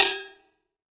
ETIMBALE H1P.wav